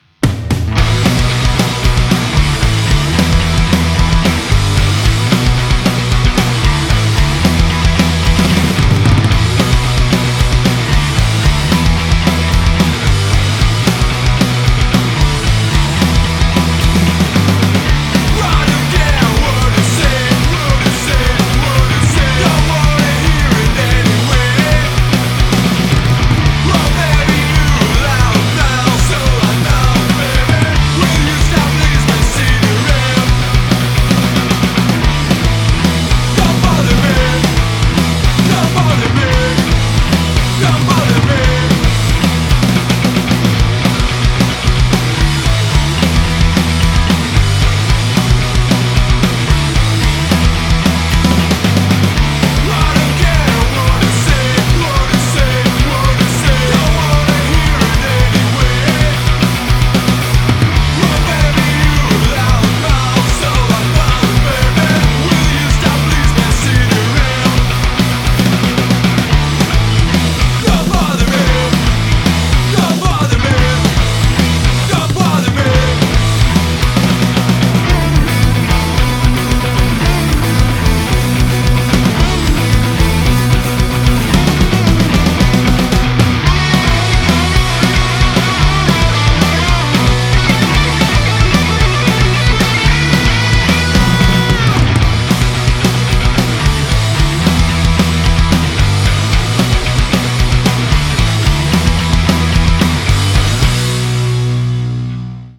Классический панк )